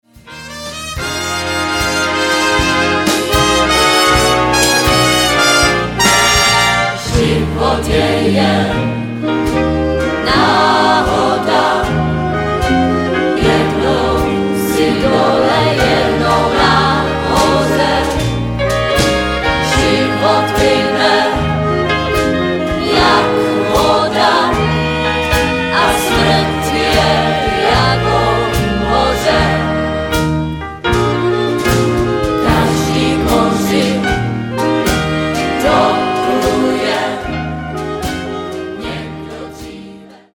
Včera již proběhl finální mix Bigbandu.
Bigband ukázečka